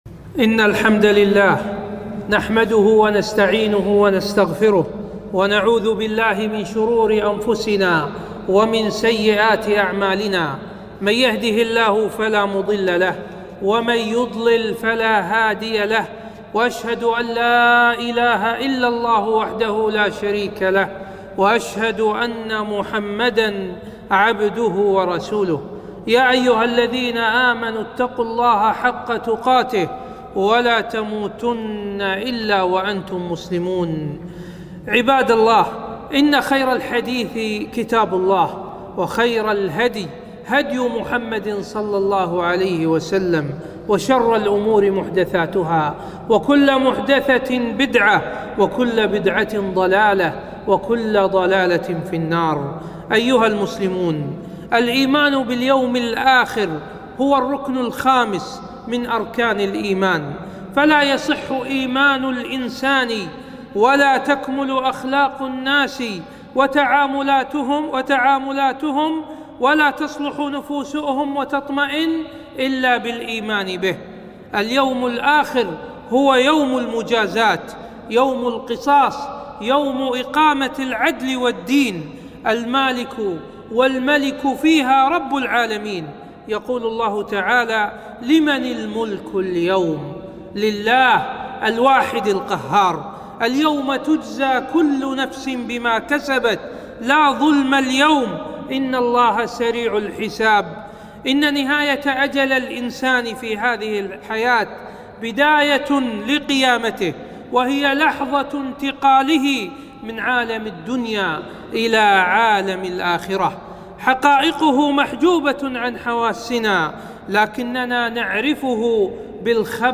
خطبة - الإيمان باليوم الآخر توجيهات حول الاختبارات